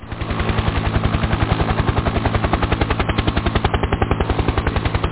heli2.mp3